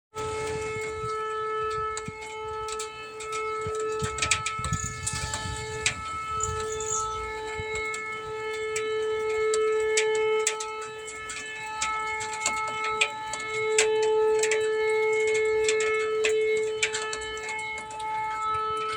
«Что-то замкнуло». На севере Петербурга обед проходит под гул сирены
Сирена
Местные говорят, что сирена звучала минимум десять минут, другие морщаться от резких звуков уже полчаса. Звуки слышны на Дибуновской улице и Липовой аллее.